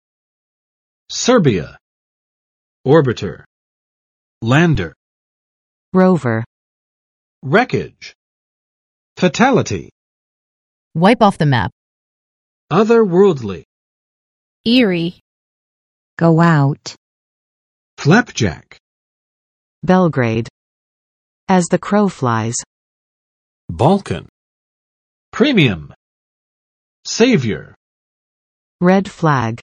[ˋsɝbɪə] n. 塞尔维亚（前南斯拉夫成员共和国名称）